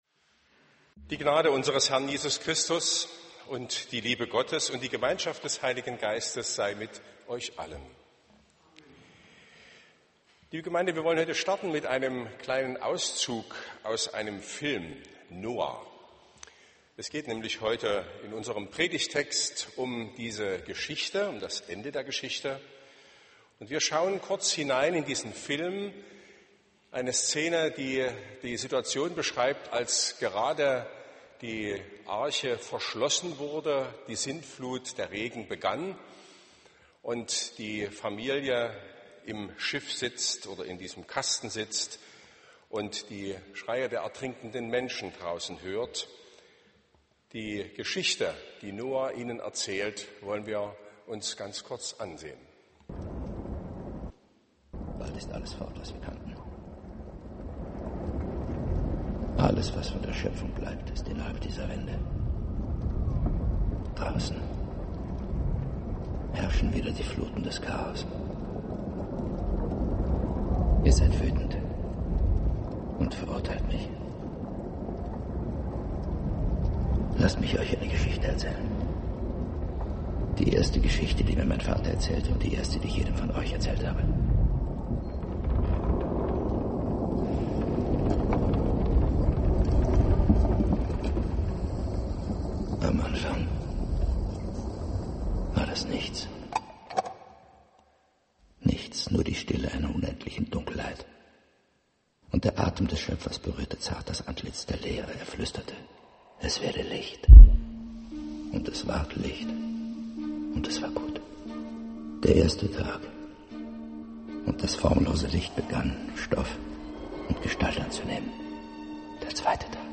Predigt vom 29.10.2017